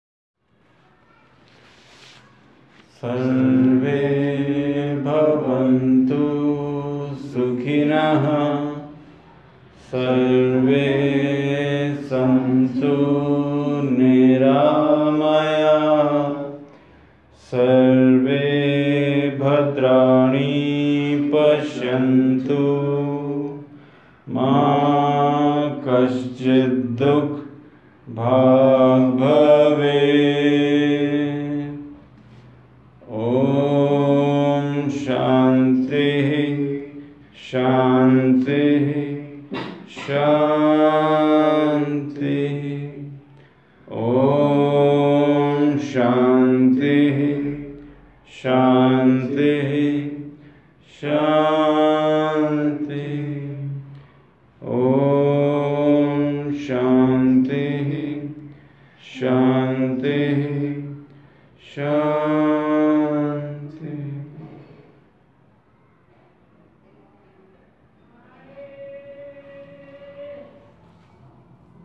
Project B: Mantra Chanting as Music